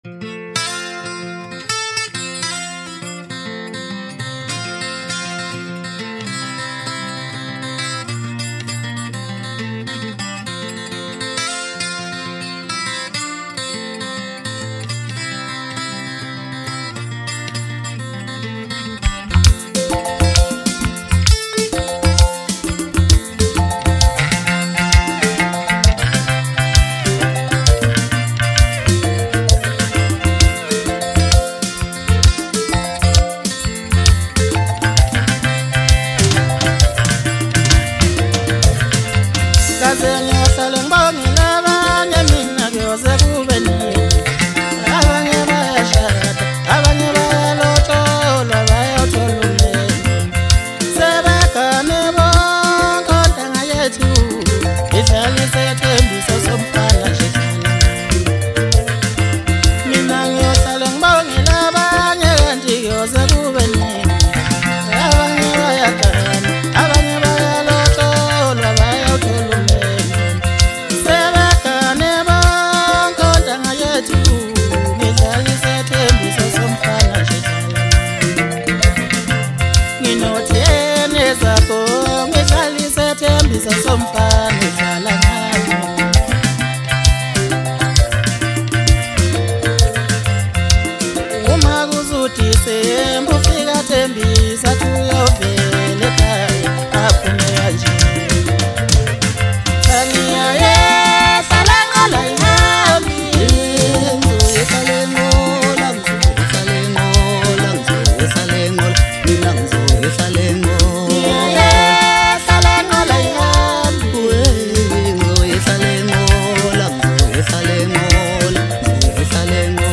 Home » Maskandi